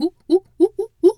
monkey_2_chatter_12.wav